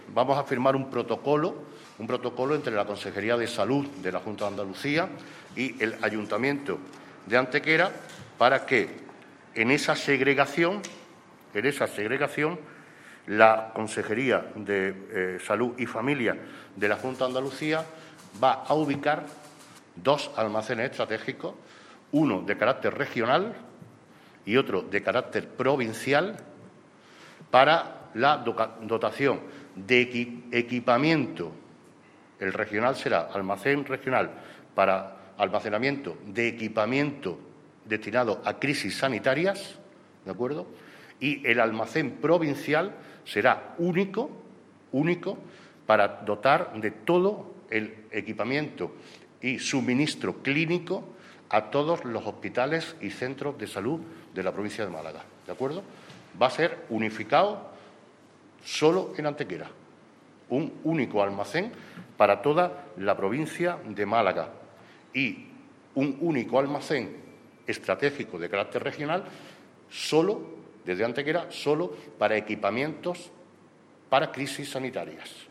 El alcalde de Antequera, Manolo Barón, y su primer teniente de alcalde, Juan Rosas, han informado hoy en rueda de prensa de la cesión a la Consejería de Salud de la Junta de Andalucía de una parcela de terreno municipal de 10.000 m2 de extensión anexa al aparcamiento general del Hospital Comarcal y con salida directa hacia las autovías A-92 y A-45 para que pueda albergar dos nuevas instalaciones estratégicas del Servicio Andaluz de Salud.
Cortes de voz